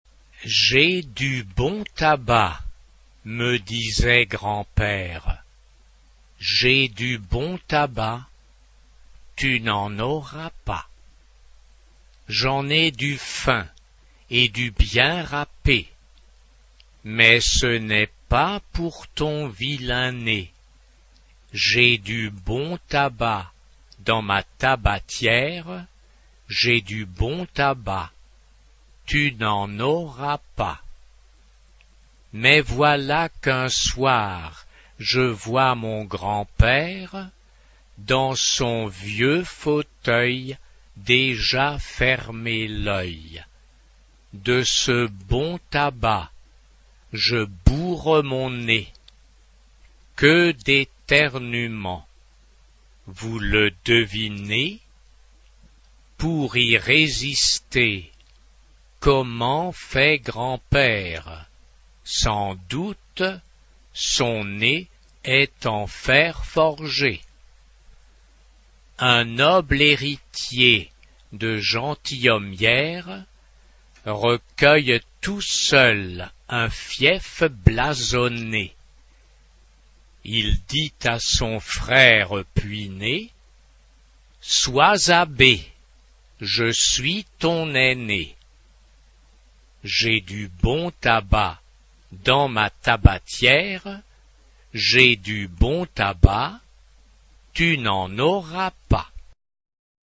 SSS (3 Kinderchor Stimmen) ; Partitur.
Kanon.
Tonart(en): F-Dur